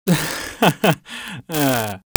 Sarcastic Laugh Male
Sarcastic Laugh Male.wav